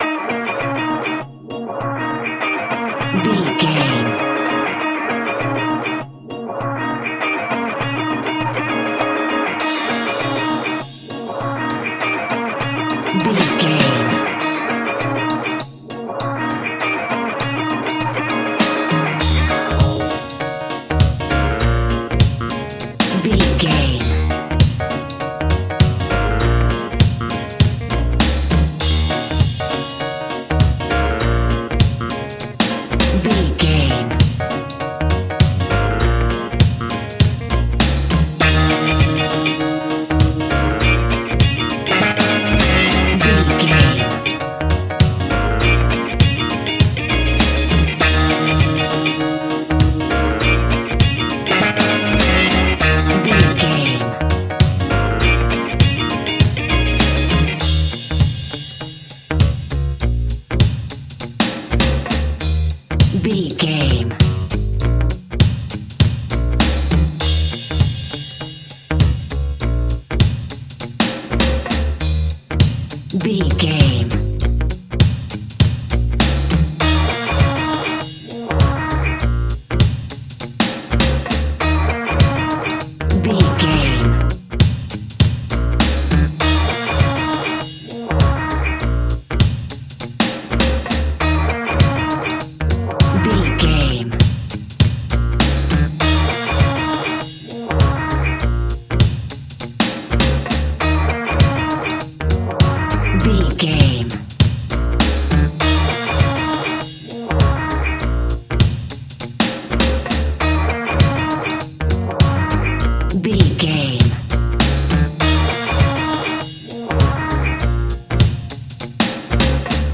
Aeolian/Minor
B♭
instrumentals
laid back
chilled
off beat
drums
skank guitar
hammond organ
percussion
horns